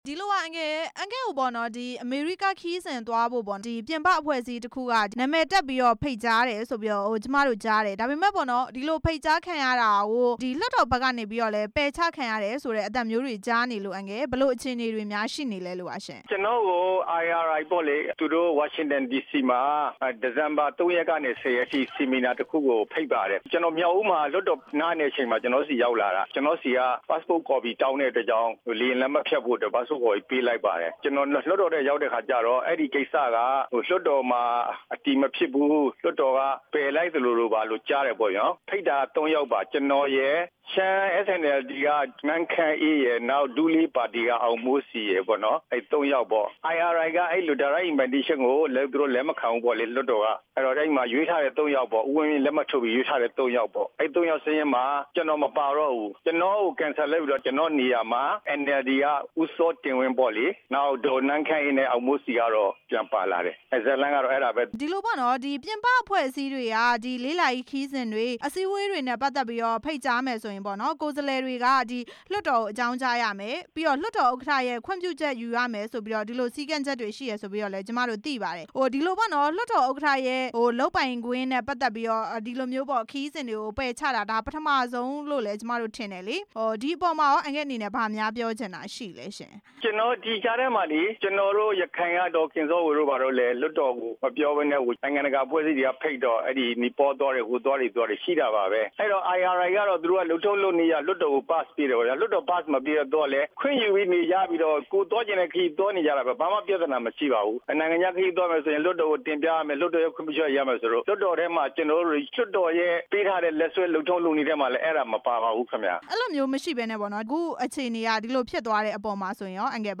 IRI က ဖိတ်ကြားခံရတဲ့ ဦးဦးလှစောနဲ့ မေးမြန်းချက်